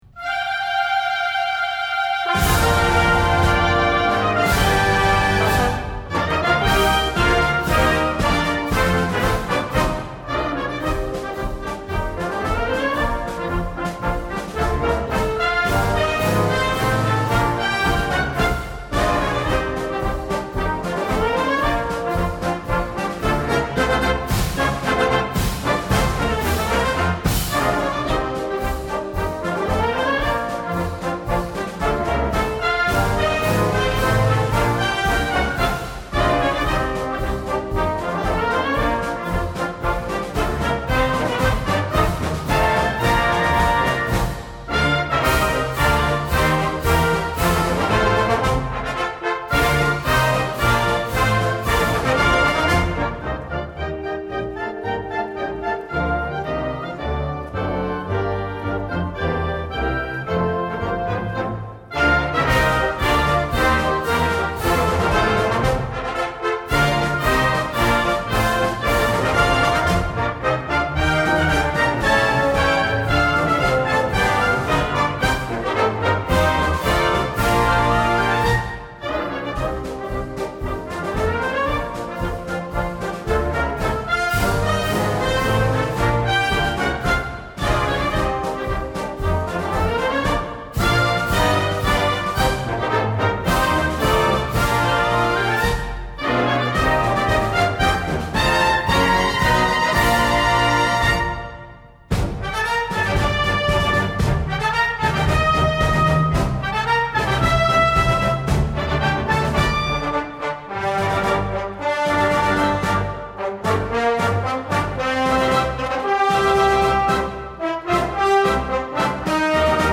Concert Wind Band Duration : 3’ – 45”